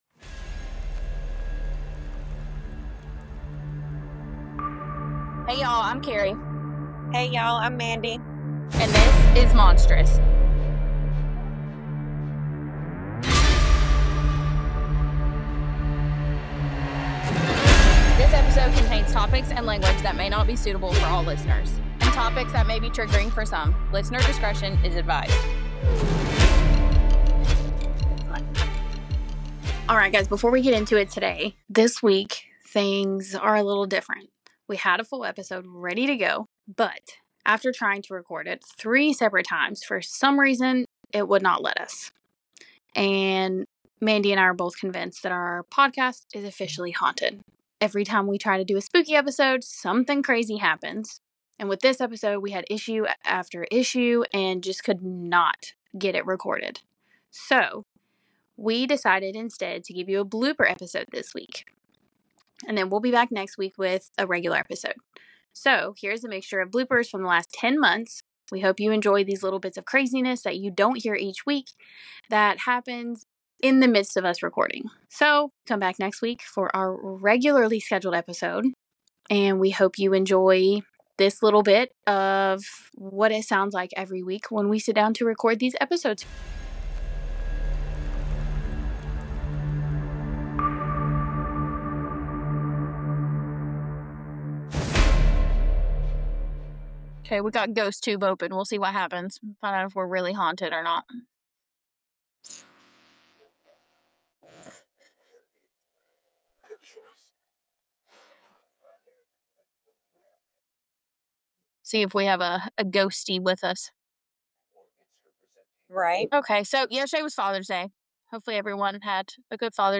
Instead, you’re getting a special bloopers episode.